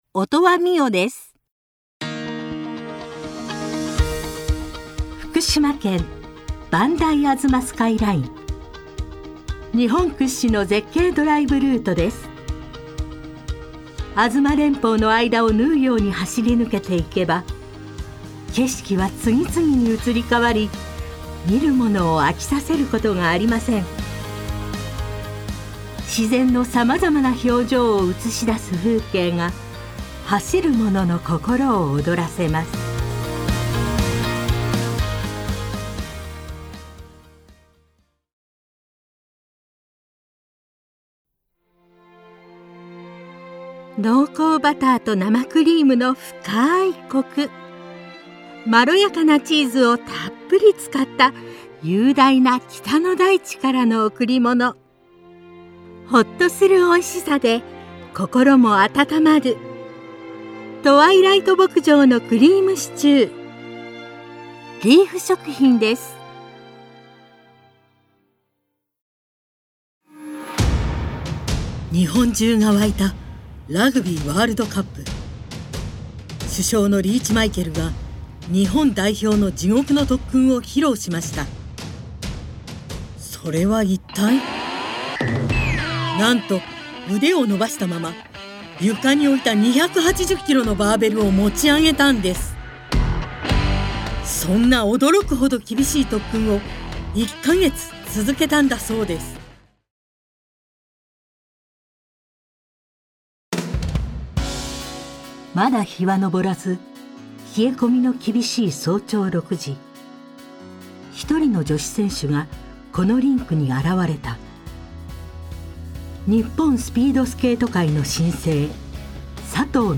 ◆ボイスサンプル◆